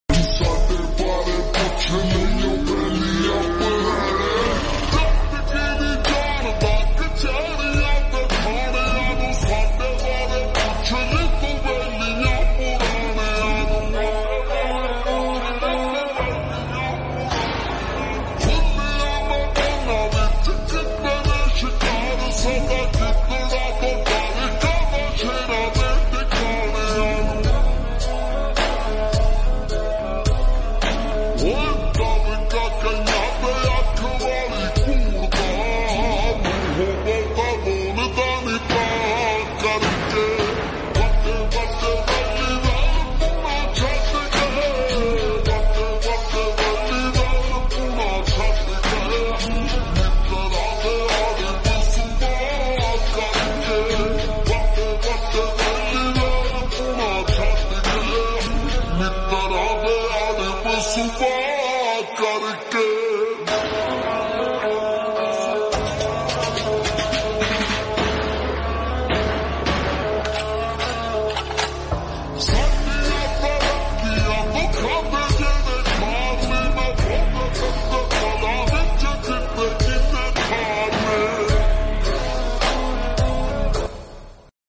PUNJABI ULTRA HD SLOWED SONG